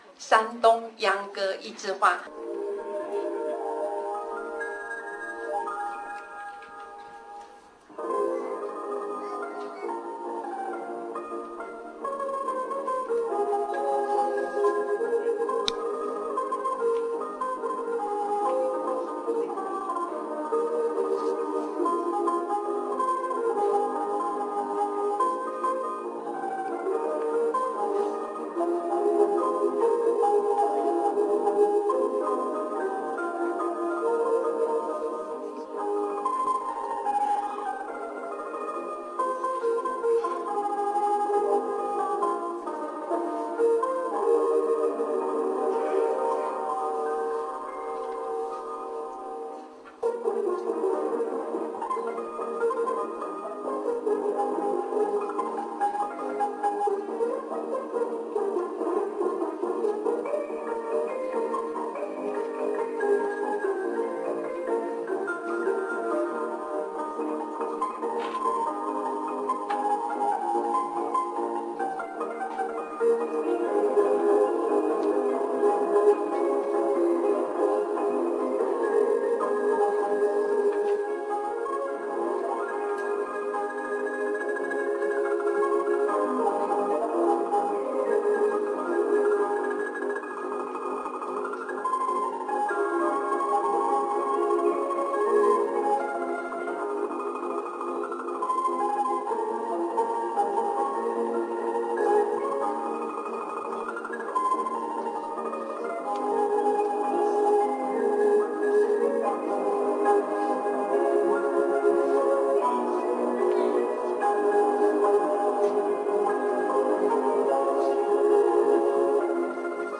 ShanDongYangGe_YiZhiHua_256k.ra